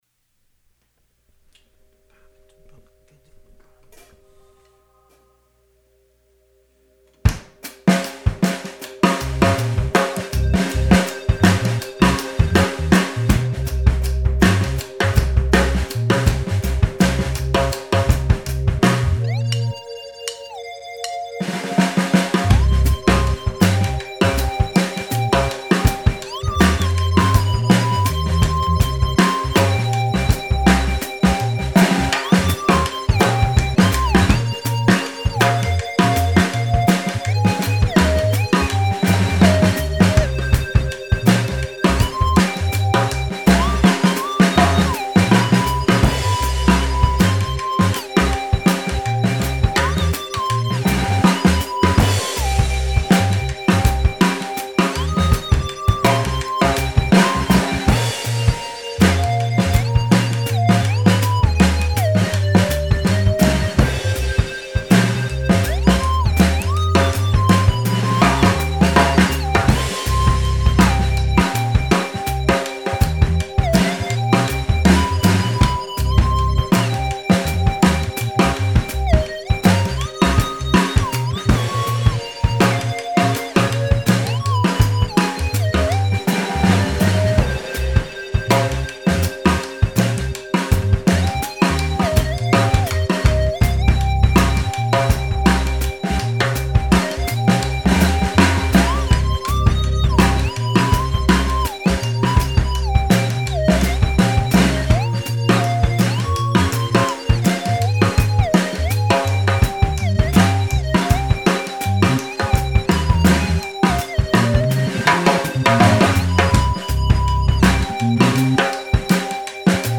Open Hi Hat Beat
01-Open-Hi-Hat-Beat.mp3